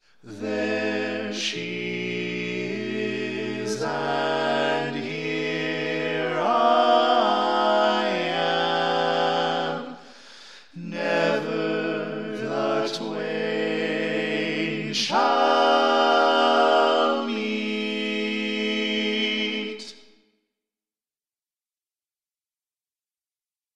Key written in: A Minor
How many parts: 4
Type: Barbershop
All Parts mix: